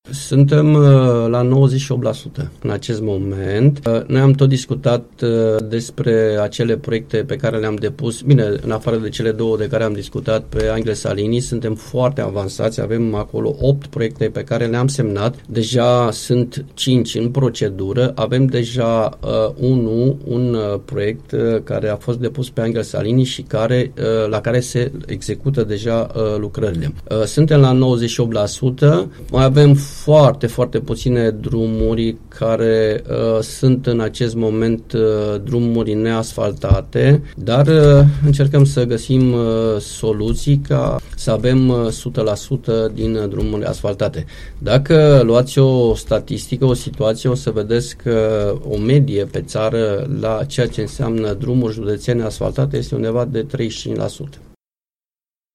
În Alba s-a atins pragul de 98% de drumuri județene asfaltate și modernizate. Este afirmația făcută la Unirea FM de vicepreședintele Consiliului Județean Alba, Marius Hațegan.